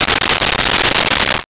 Plastic Wrap 01
Plastic Wrap 01.wav